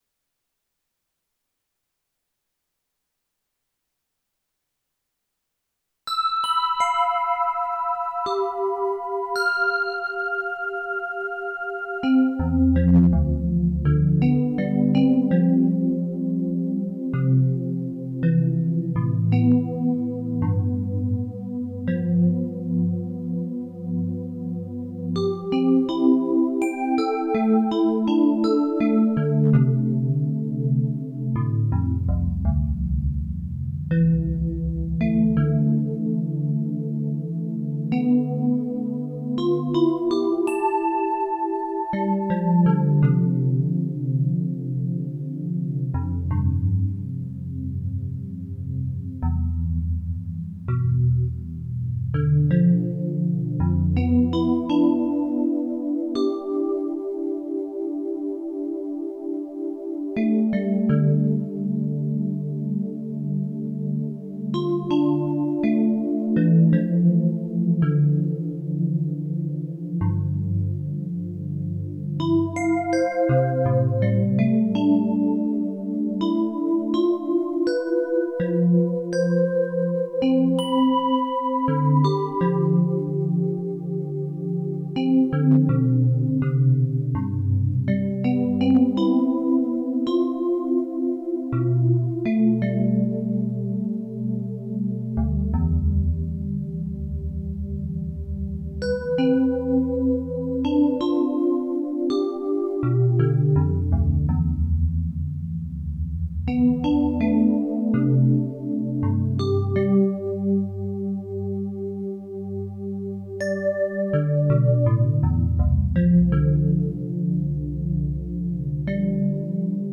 Le noisetier en fleur et bourgeon enregistré sur le chemin à l’entrée du jardin botanique au couvet des fleurs à Saint Maximin le 21 février à partir de 14h.42